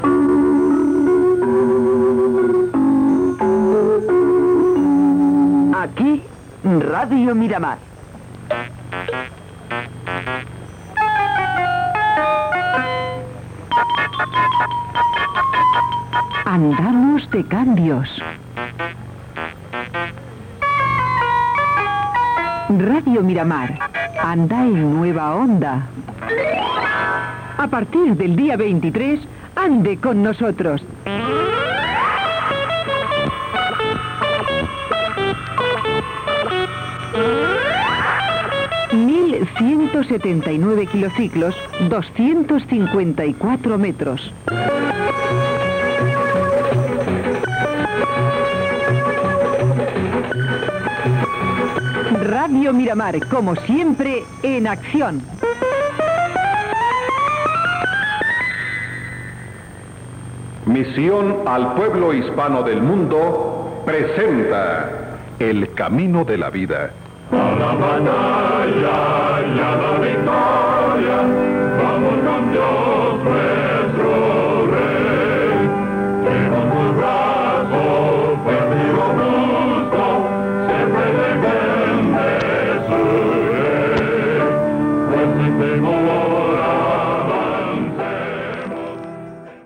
Identificació i avís del canvi de freqüència. Careta del programa evangèlic produït per Misión al pueblo hispano del mundo.